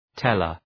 {‘telər}